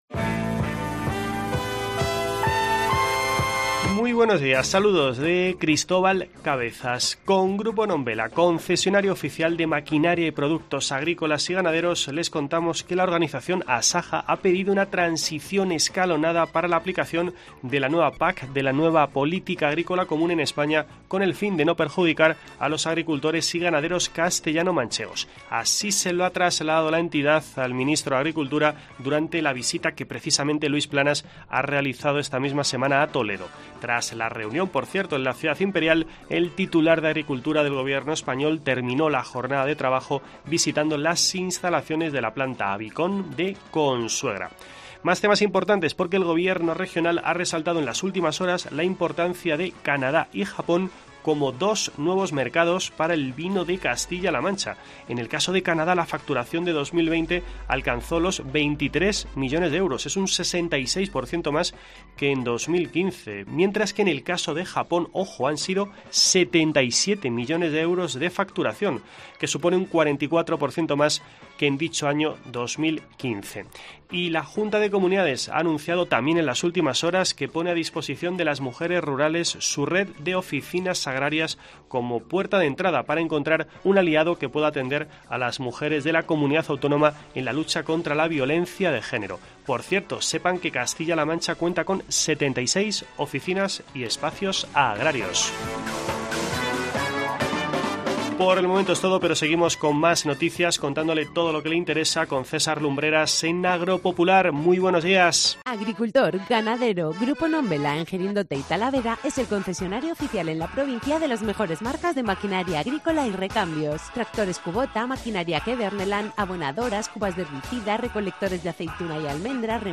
Escucha en la parte superior de esta noticia toda la actualidad del mundo del campo en nuestro boletín informativo semanal.